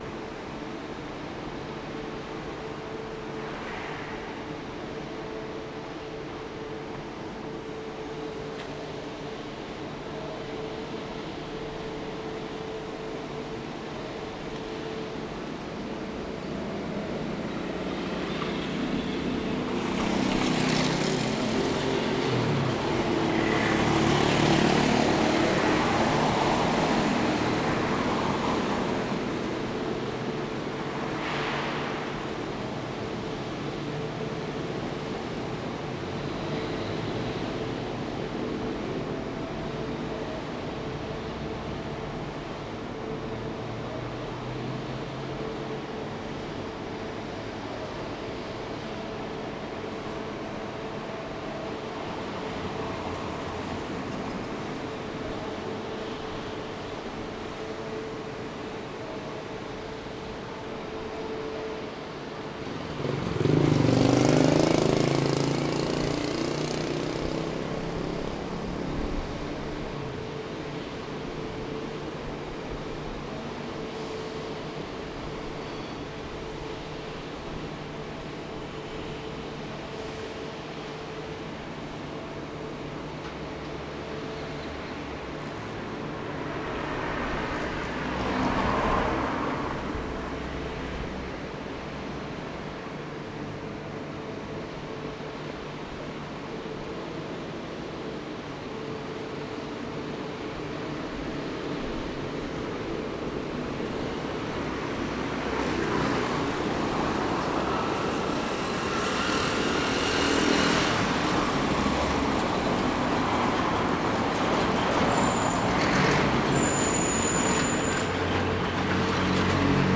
Paisajes Sonoros de Rosario archivos - Página 28 de 31 - Sonidos de Rosario
psr-velez-sarfield-y-pje-isaacs.mp3